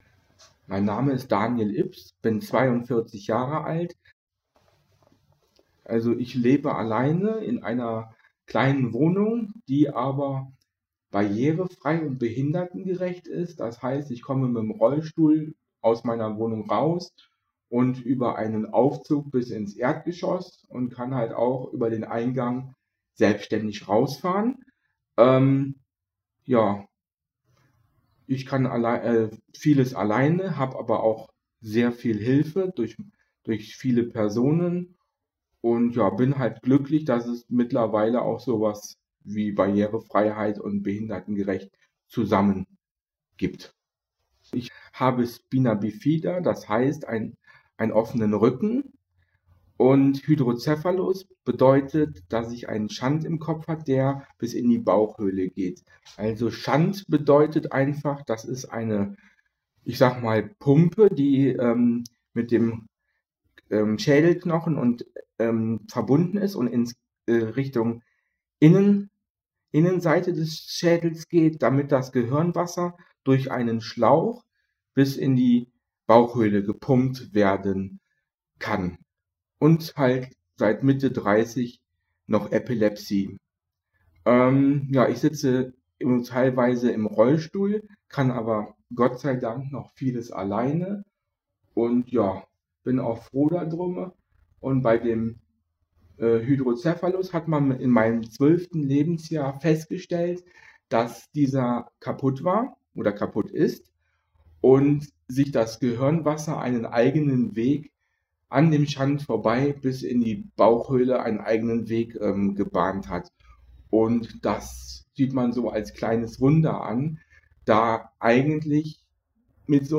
Bei den Aufnahmen, die oftmals in den Räumen der Waschbar stattfanden, ging es nicht um Perfektion, sondern um das wirkliche Leben. So begleiten schon mal Kirchenglocken oder auch Baustellengeräusche das gesprochene Wort.